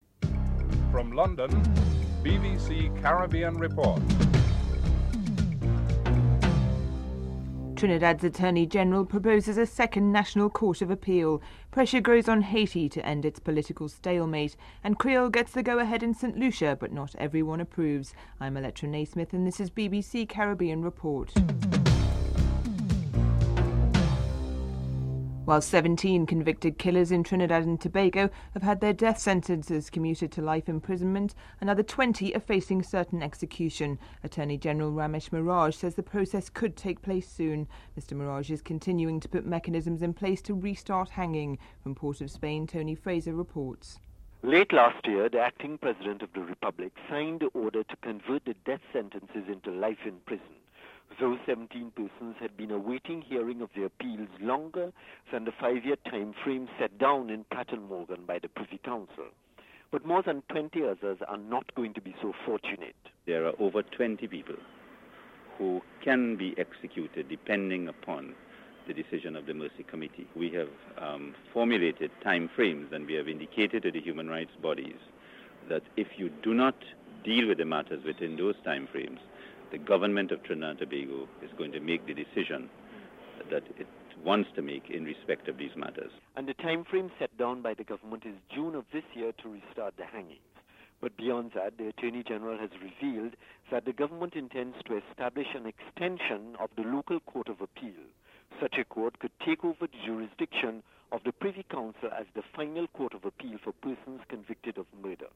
5. Former Governor of Montserrat, Frank Savage will become the next Governor of the British Virgin Islands. Mr. Savage comments on what he expects for his new post (06:34-09:45)